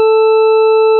Sur les différentes représentations des courbes suivantes, les fréquences et les amplitudes sont identiques.
une harmonique s'ajoute sur la courbe parfaite